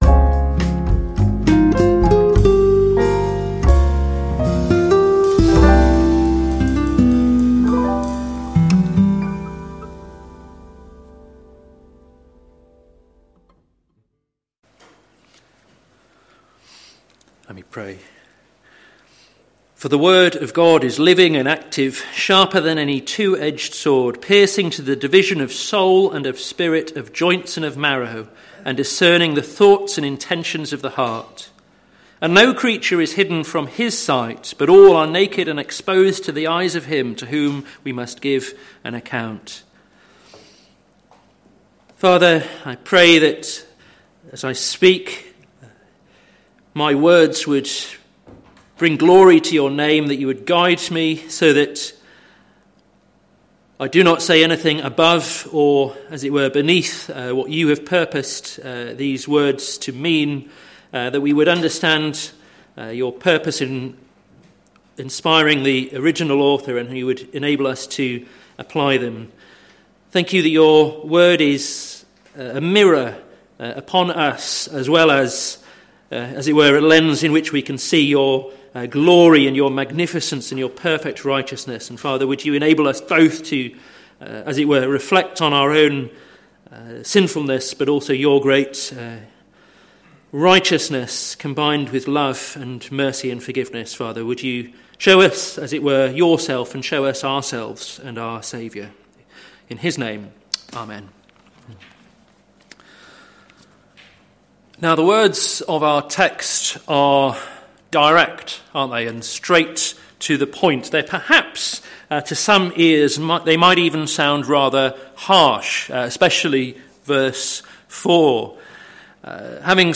Sermon Series - Caught in Two Minds - plfc (Pound Lane Free Church, Isleham, Cambridgeshire)